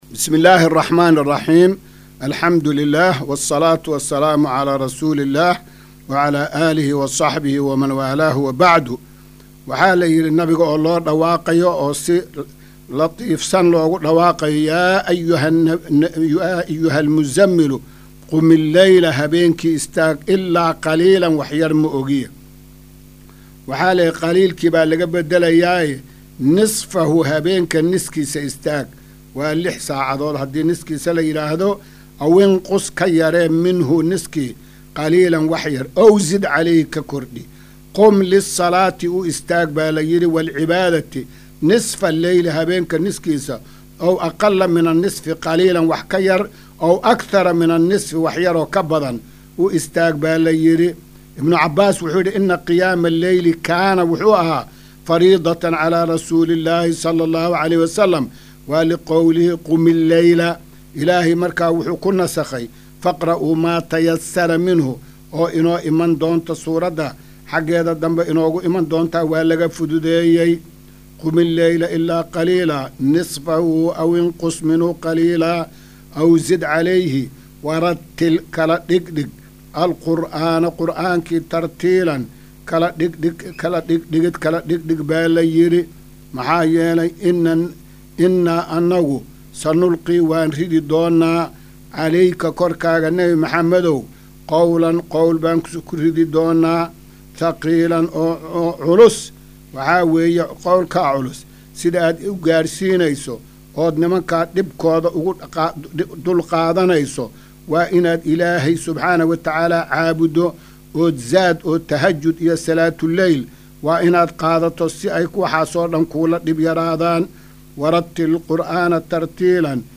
Maqal:- Casharka Tafsiirka Qur’aanka Idaacadda Himilo “Darsiga 275aad”